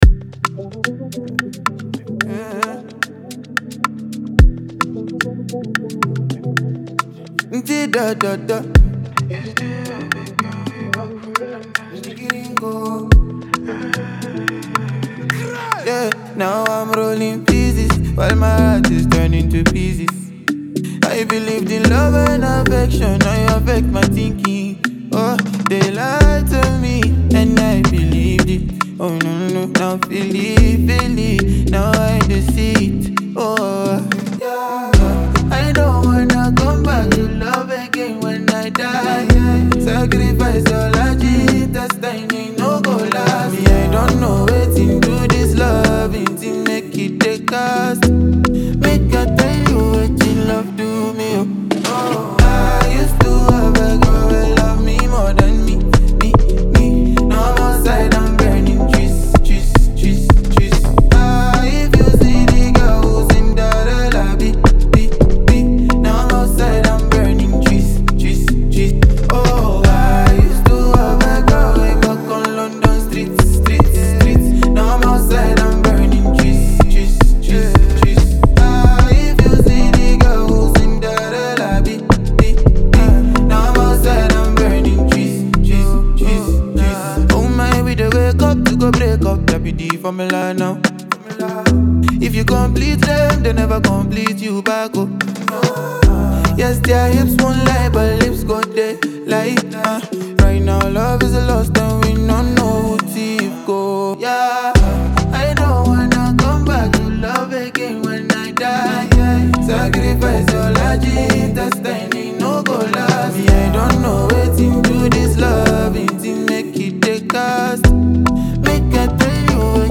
Smooth vocals, dreamy rhythms, and a steady flow.